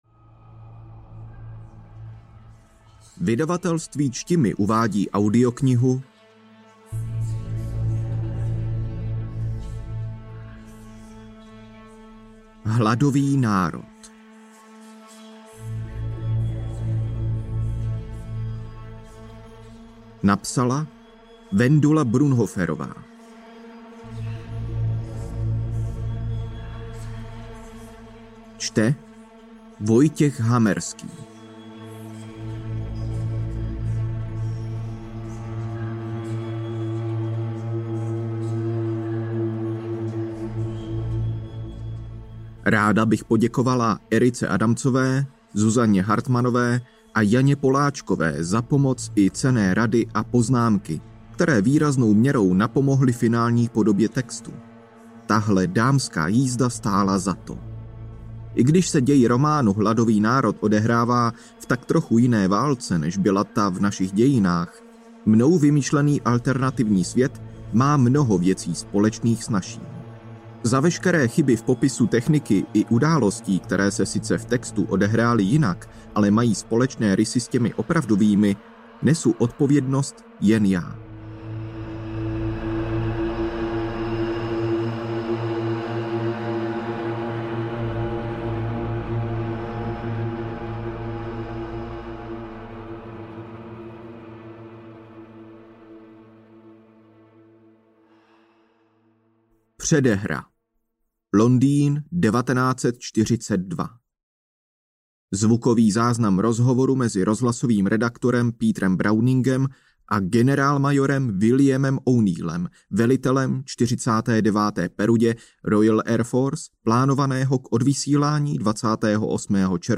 Hladový národ audiokniha
Ukázka z knihy
Nastává čas překvapivých spojenectví, hrdinských činů a soubojů se smrtí, která číhá všude kolem.„Music by Scott Buckley“